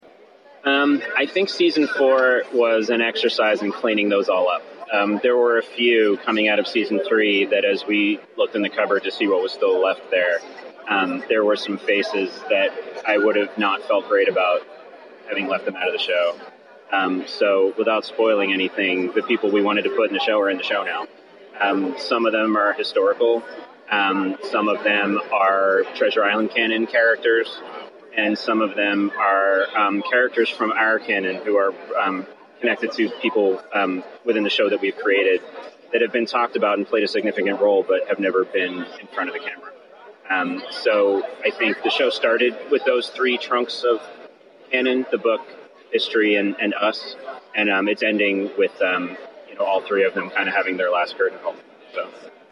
Hi-Def Ninja was able to sit down with the cast of BLACK SAILS at NYCC to talk to them about the show and its fourth and final season.